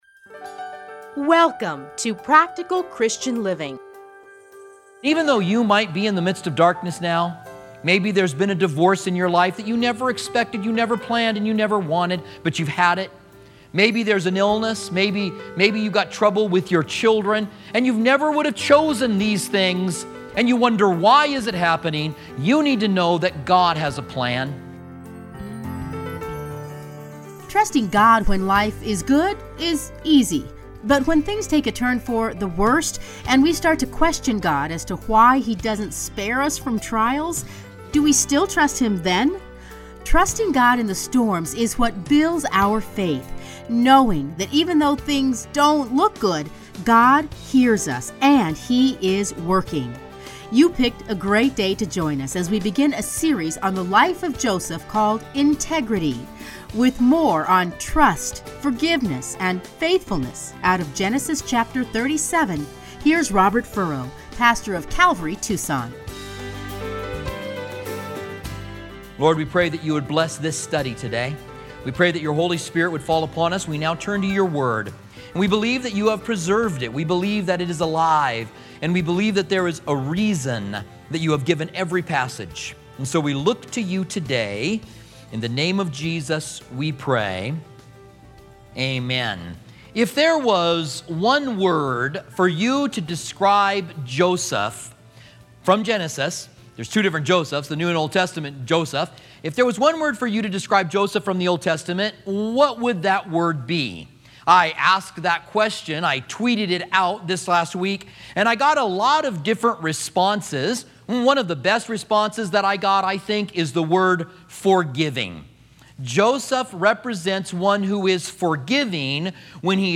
teachings are edited into 30-minute radio programs